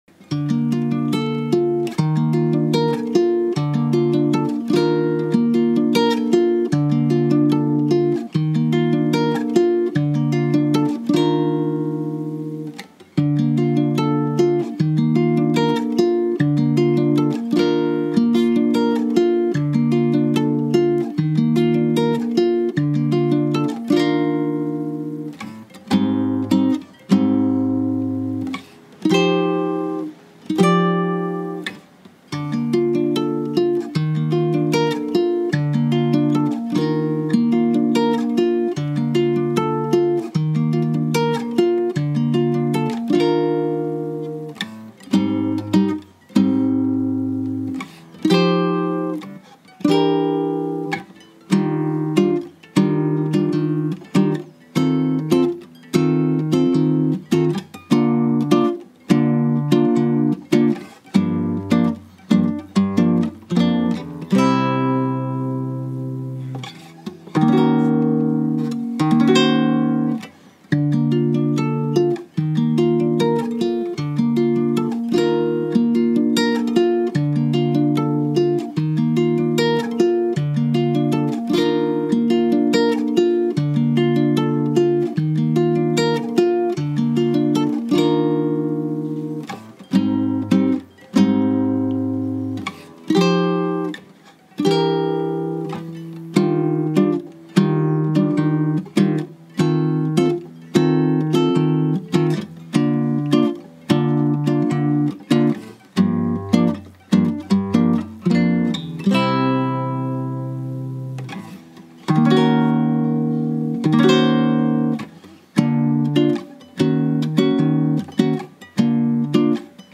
Tonalidade OriginalSi Bemol Maior (Bb)
Compasso4/4
AndamentoModerato (Balada)
BaseViolão Acústico Solo
Respeite as pausas do violão para criar contraste.